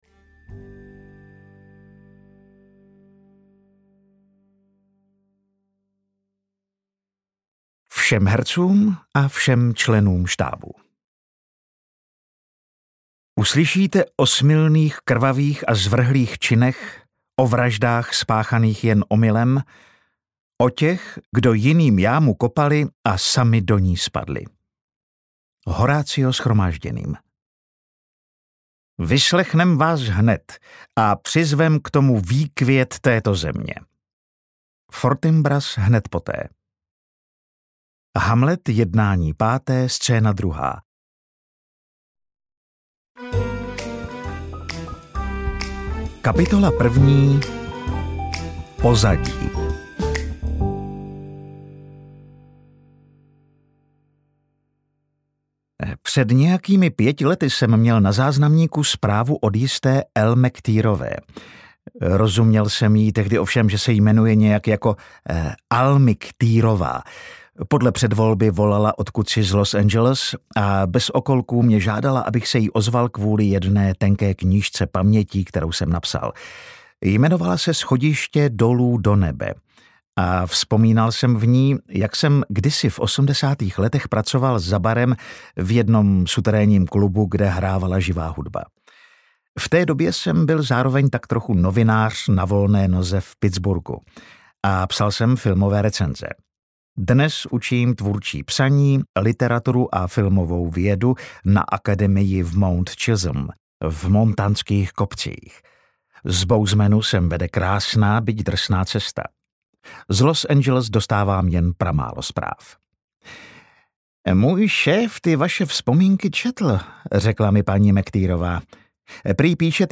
Biják: Jak se dělá velkofilm audiokniha
Ukázka z knihy